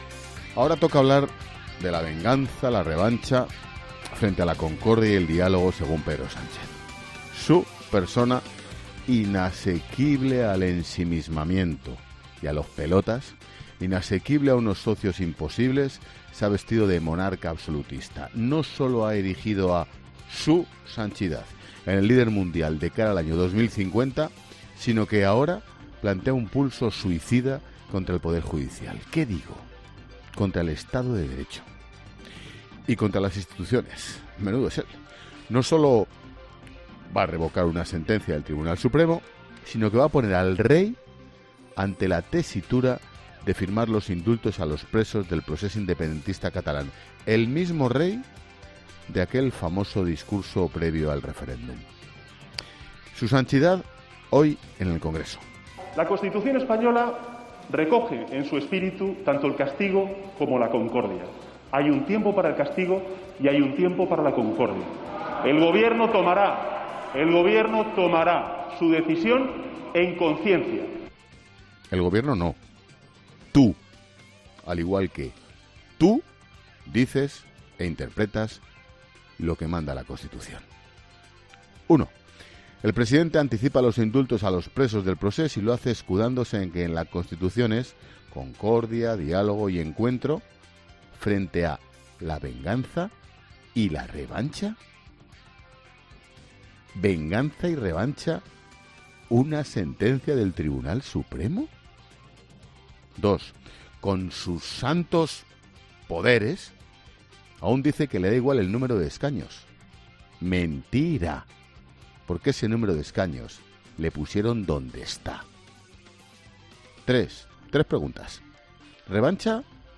Monólogo de Expósito
El director de 'La Linterna', Ángel Expósito, reflexiona en su monólogo sobre las palabras de Sánchez este miércoles en el Congreso